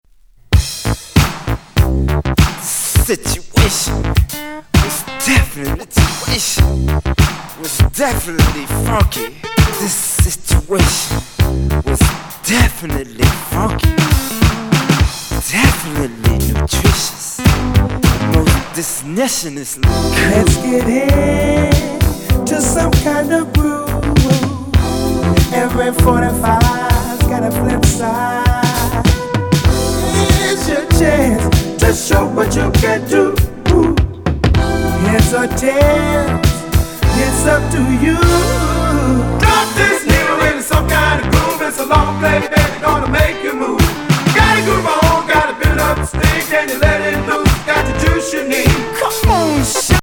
モダン・ソウル
P-FUNKな素敵アルバム！